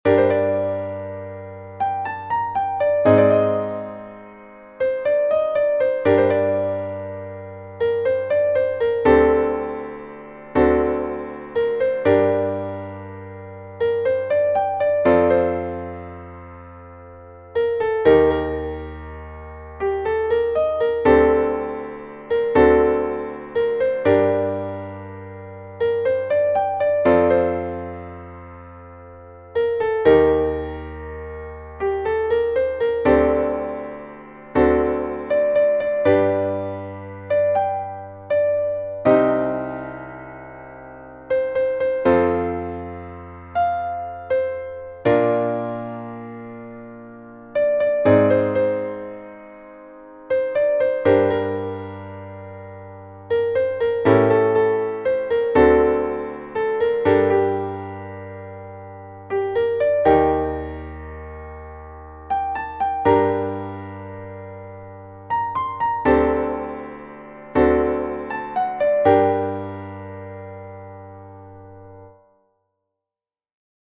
イントロもオリジナルのを４小節付けました。
これはキーがGマイナーですから、Abメジャーが「ナポリ」になるんですよね。
しかも、そちらの方がしっかり両手で弾いていて音域も広かったんですよ。